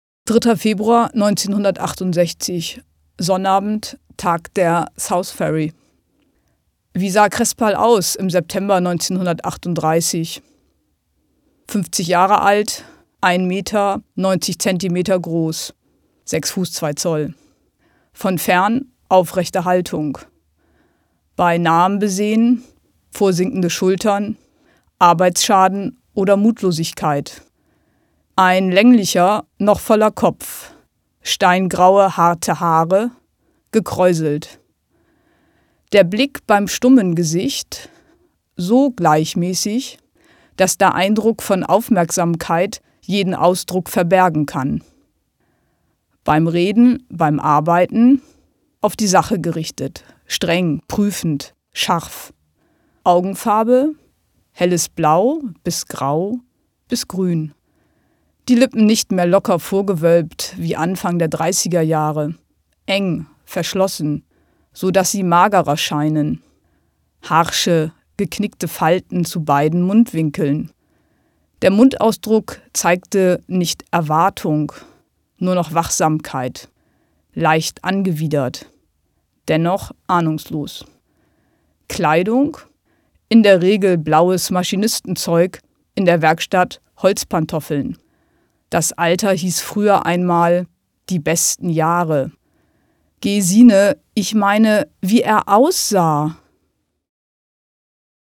Eine Stadt liest Uwe Johnsons Jahrestage - 3.